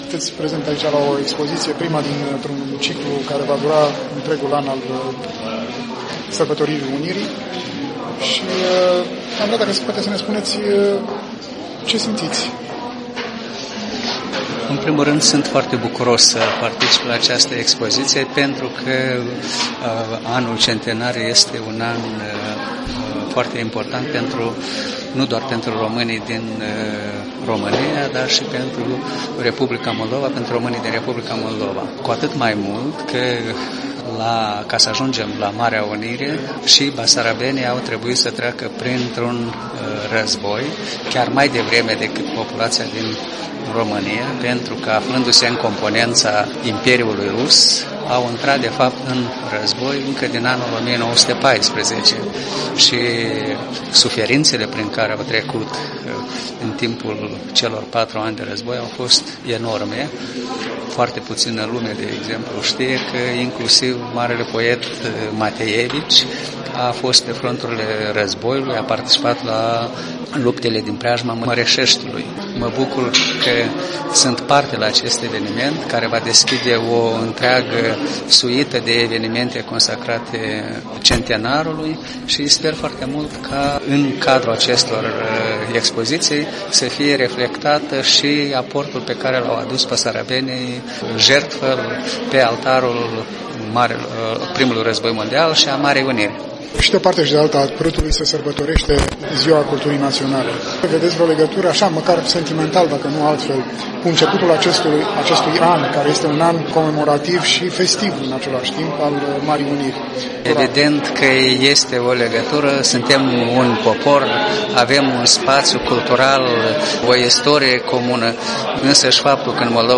Interviu cu ambasadorul Republicii Moldova la București.